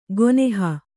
♪ goneha